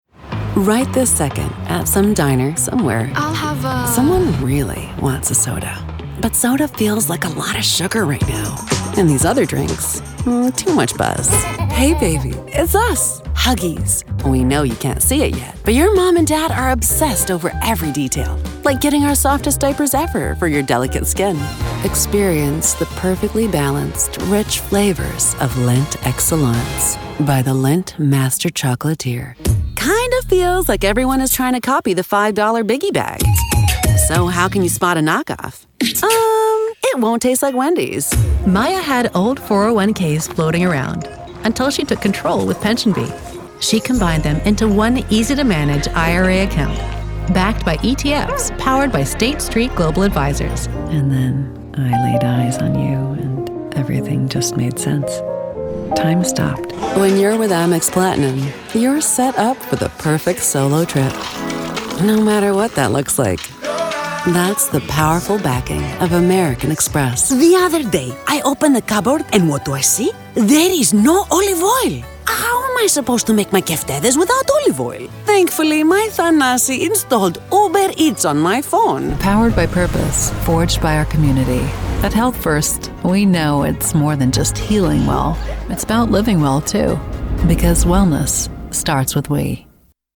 Commercial Showreel
Female
American Standard
Husky (light)
Soft
Warm